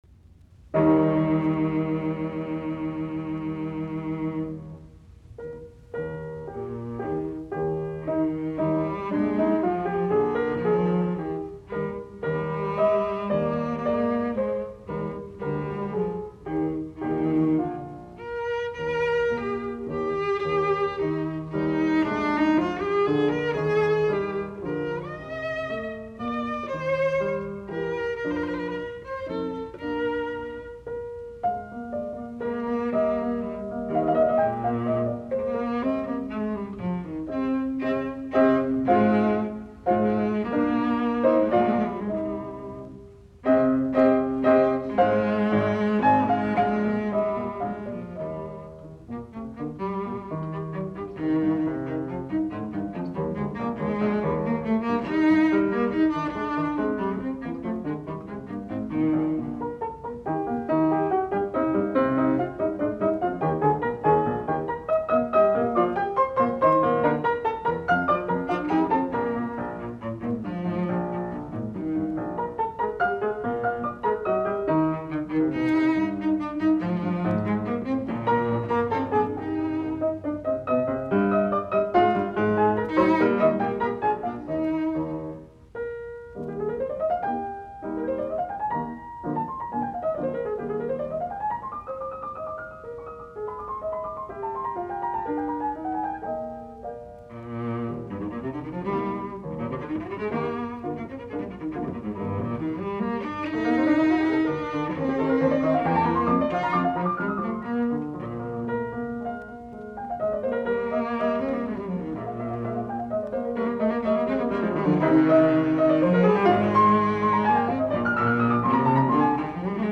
Muunnelmat, sello, piano
F-duuri
Soitinnus: Sello, piano.